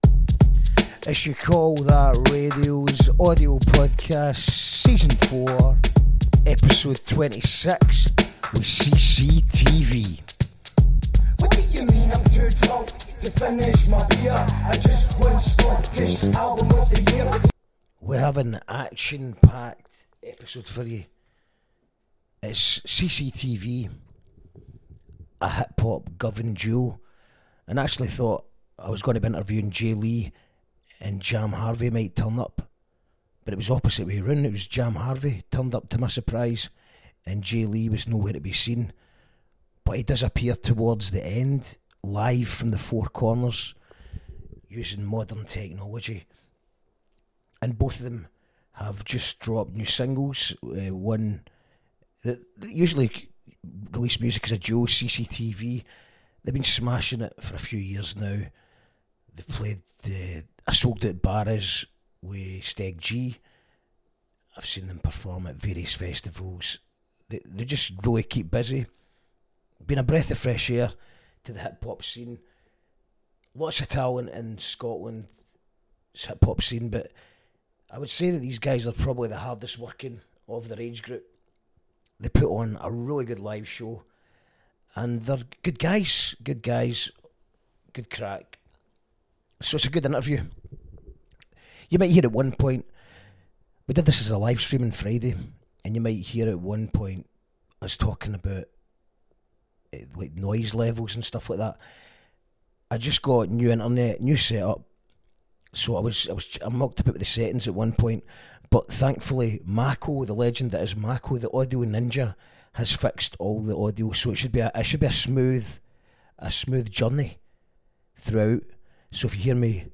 A chat with Govan rap sensations CCTV about their new singles and upcoming gigs.